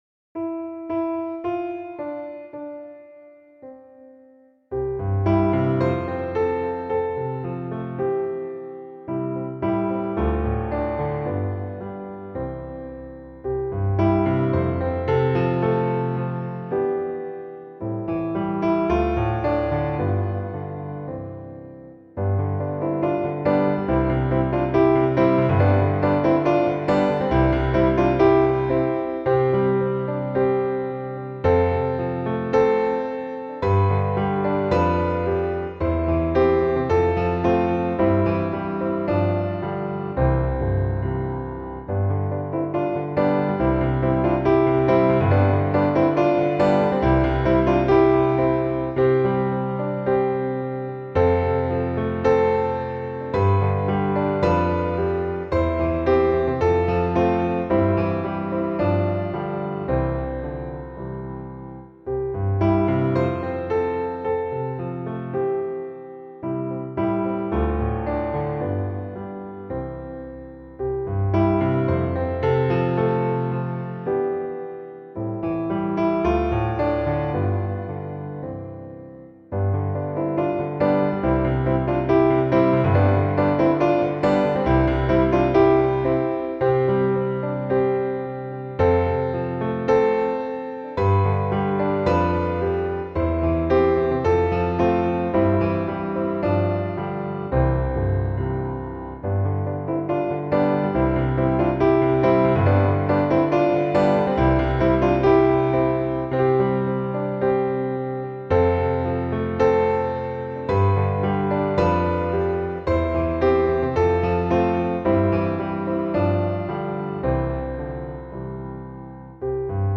Aby ułatwić przygotowanie się do nagrania, poniżej prezentujemy podkłady muzyczne, w trzech wersjach.